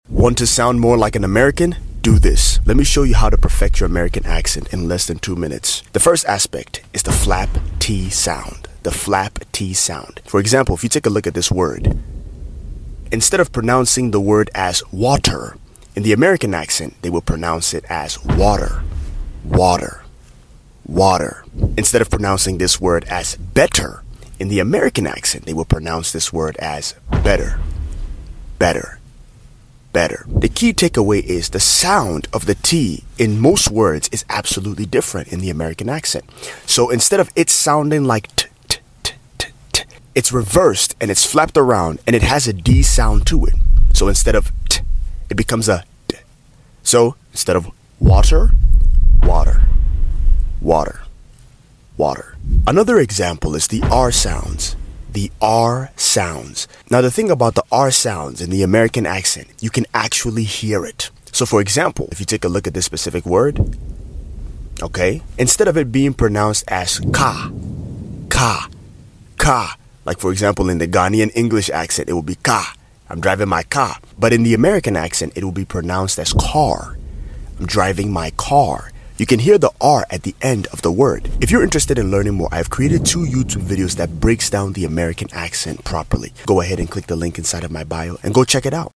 American 🇺🇸 English Accent in sound effects free download
American 🇺🇸 English Accent in Less than 2 Minutes.